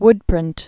(wdprĭnt)